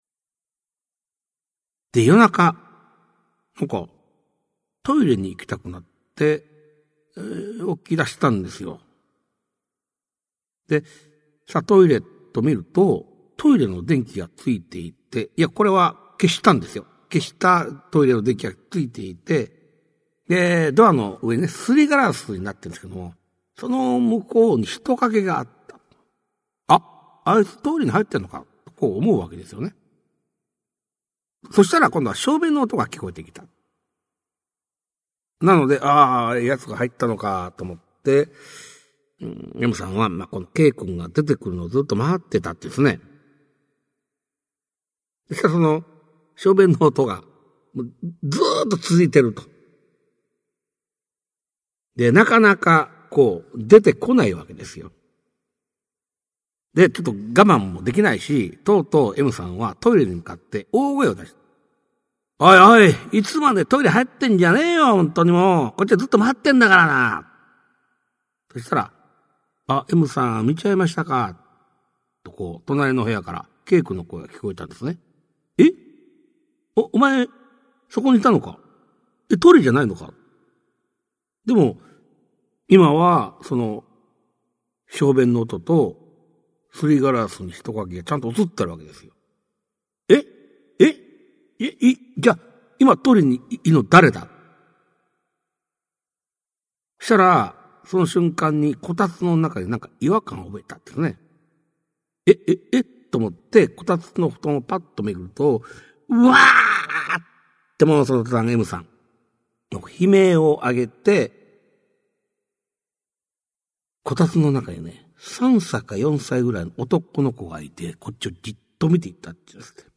[オーディオブック] 市朗怪全集 五十九
実話系怪談のパイオニア、『新耳袋』シリーズの著者の一人が、語りで送る怪談全集! 1990年代に巻き起こったJホラー・ブームを牽引した実話怪談界の大御所が、満を持して登場する!!